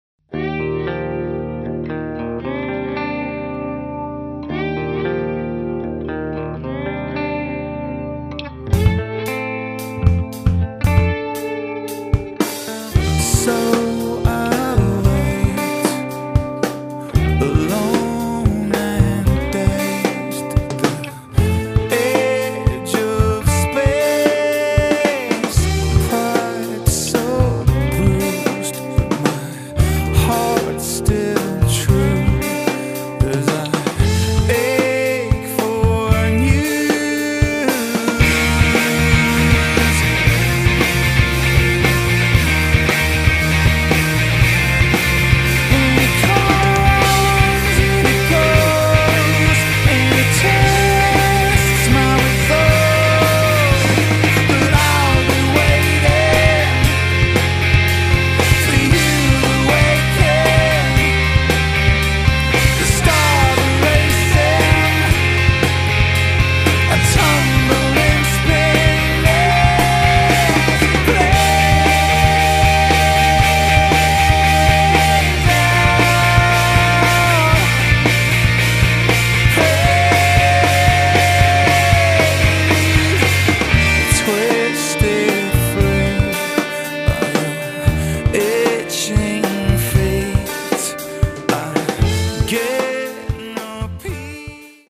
Microphone for Diezel guitar amp recording
Hi All, Thought i'd share a quick clip of my bands latest demo where you can hear the AT4040 and SM57 together on the guitar parts. All guitars were recorded with a Diezel Einstein combo. Mics were about 10cm away from the grill with the 57 dead centre and the 4040 just to the right of it. Hopefully shows this amp is just as good with softer styles as it is with the rockier stuff.